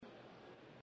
تاريخ النشر ١٧ رمضان ١٤٣٩ هـ المكان: المسجد الحرام الشيخ